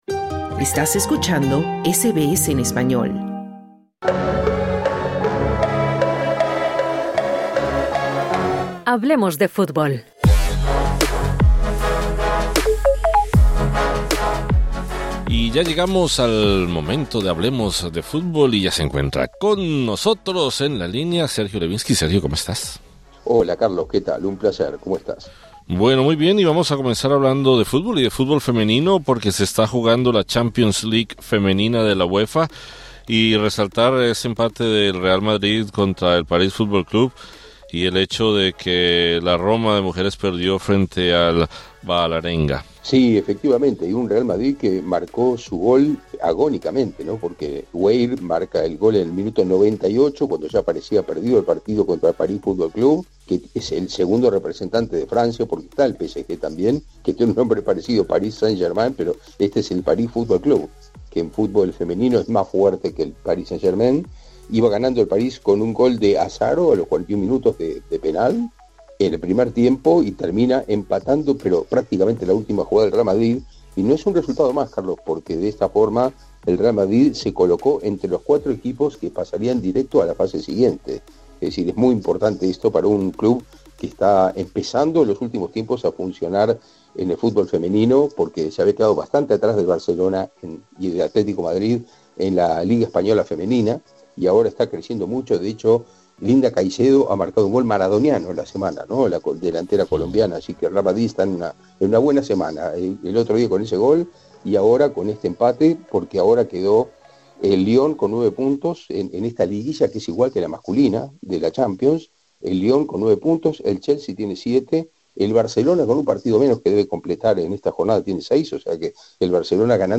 El argentino se presentó en la que fue su casa durante años, sin avisar al club y generando gran desconcierto en el propio club catalán. Escucha el resumen de noticias sobre fútbol de este miércoles 12 de noviembre de 2025.